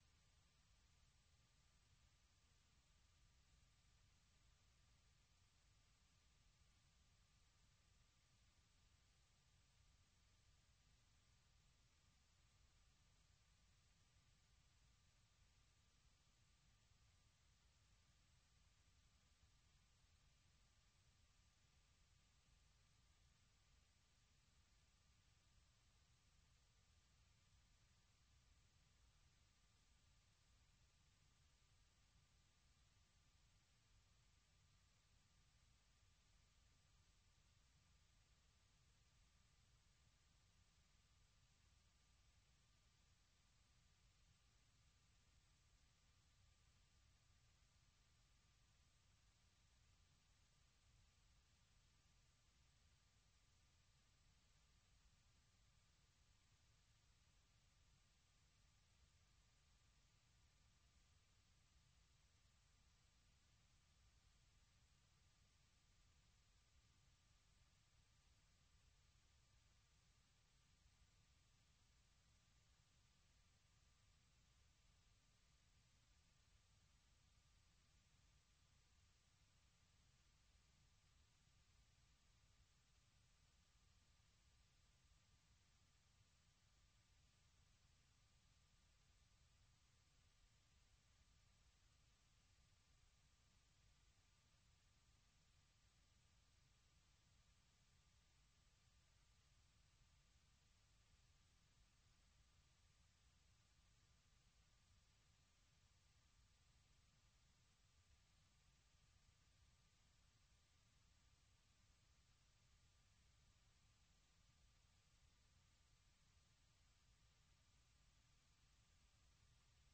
Allocution du président Donald Trump devant la session conjointe du Congrès
Vous suivez sur VOA Afrique l'édition spéciale sur l’allocution du président américain Donald Trump devant une session conjointe du Congrès, suivi de la réponse du Parti démocrate.